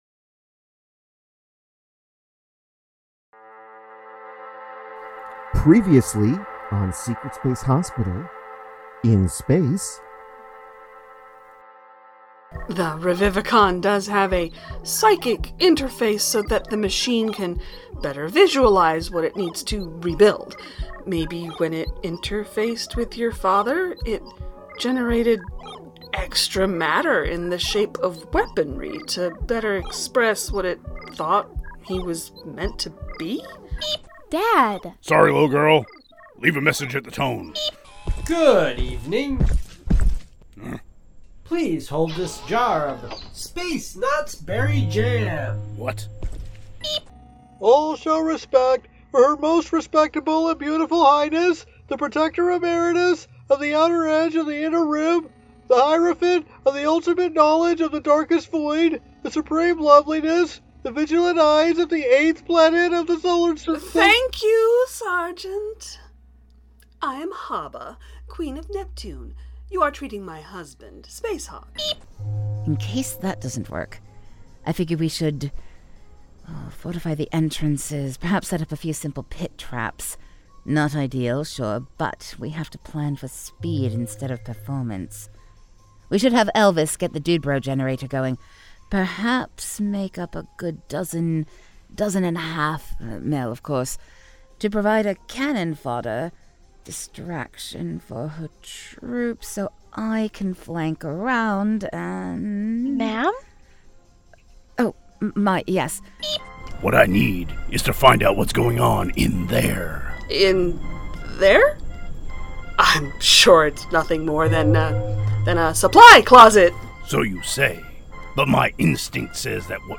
The Ocadecagonagon Theater Group
best audio drama